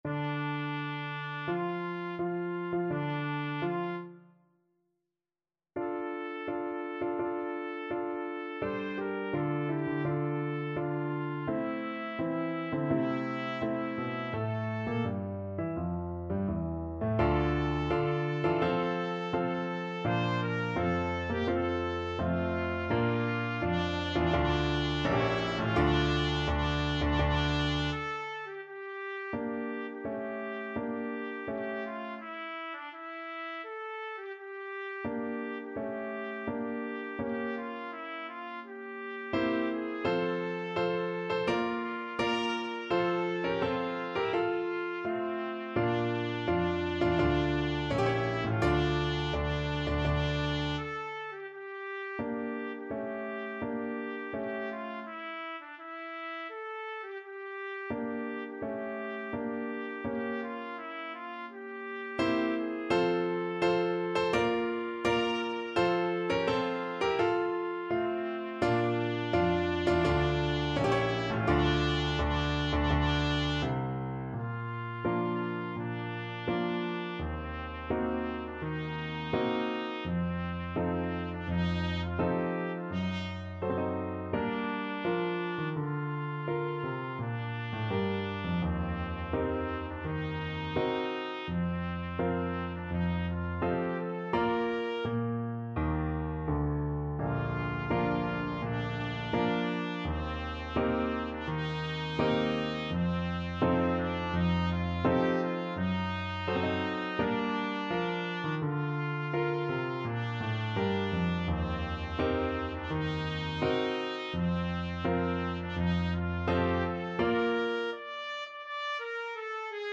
Trumpet
D minor (Sounding Pitch) E minor (Trumpet in Bb) (View more D minor Music for Trumpet )
Tempo di Marcia =84
Classical (View more Classical Trumpet Music)